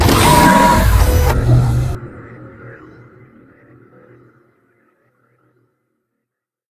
combat / enemy / droid